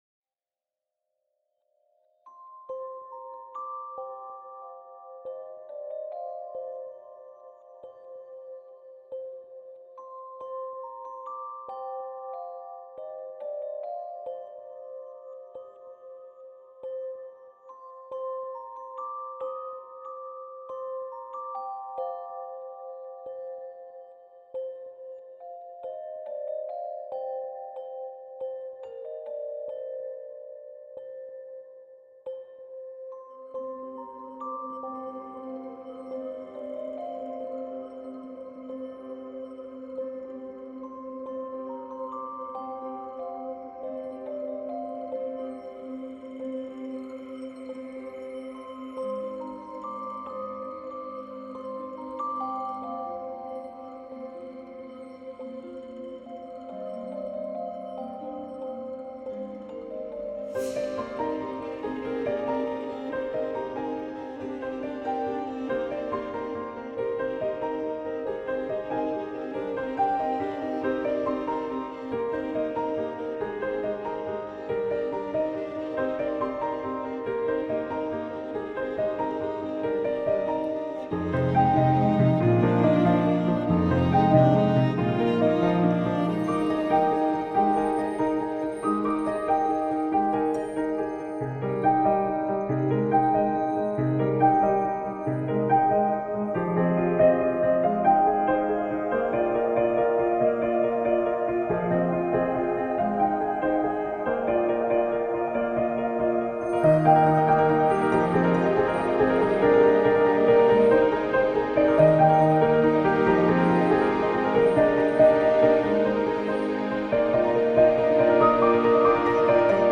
موسیقی کنار تو